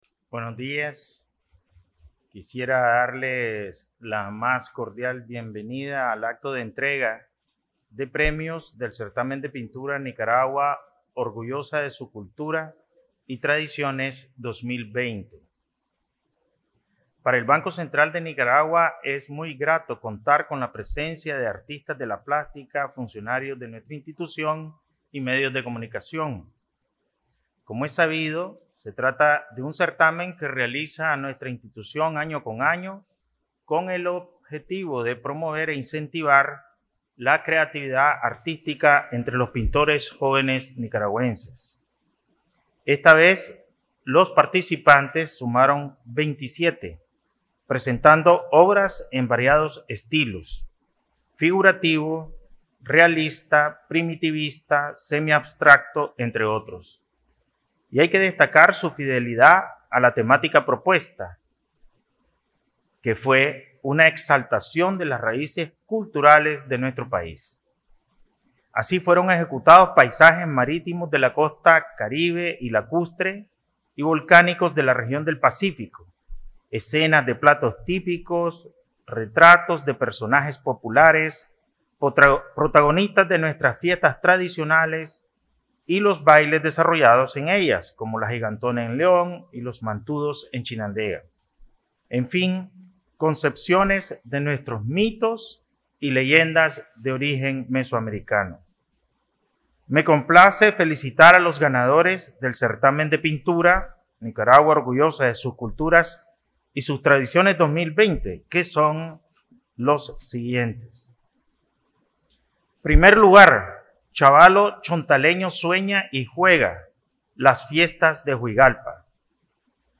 El Banco Central de Nicaragua (BCN) efectuó el 16 de febrero de 2021, la ceremonia de premiación del Certamen de Pintura "Nicaragua, Orgullosa de su Cultura y Tradiciones 2020", convocatoria anual de la institución, cuyo objetivo es promover e incentivar la creatividad artística entre los pintores jóvenes del país.
Palabras del Presidente del BCN, Ovidio Reyes R.